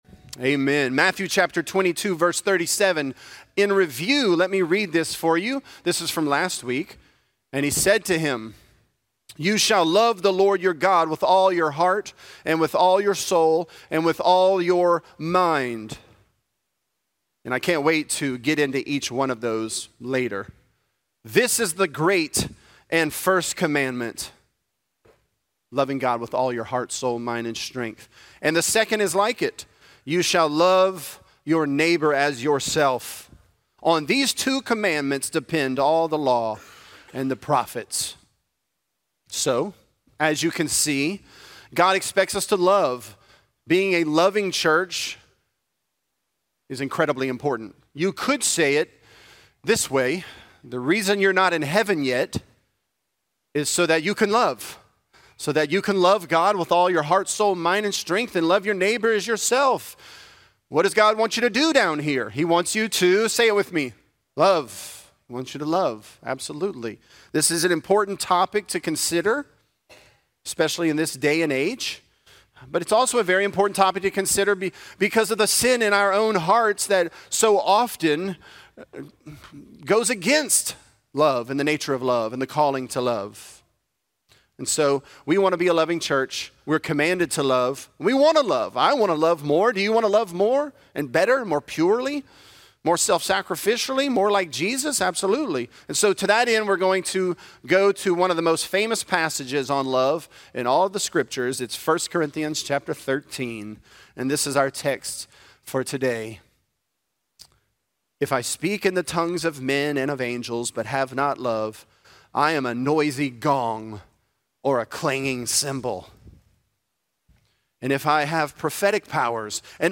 The Greatest Commandment: Love Is Patient And Kind | Lafayette - Sermon (Matthew 22)